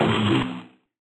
NM - Ends Perc.wav